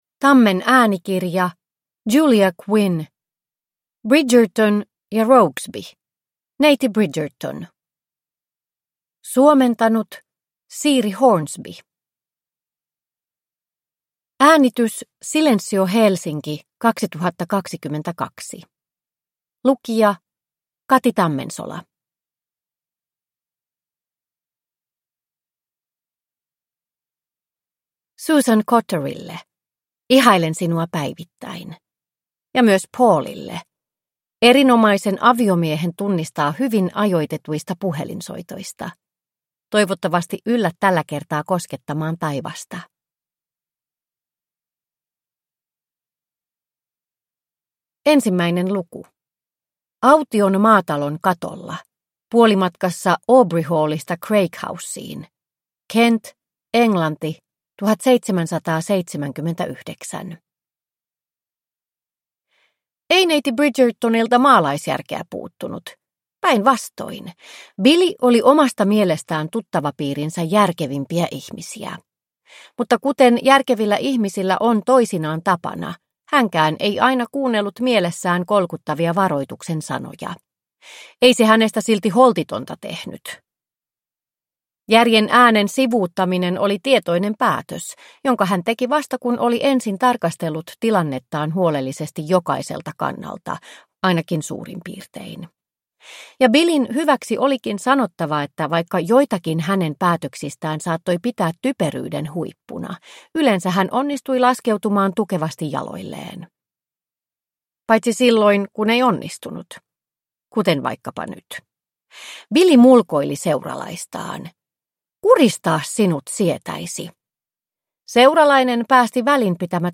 Bridgerton & Rokesby: Neiti Bridgerton – Ljudbok – Laddas ner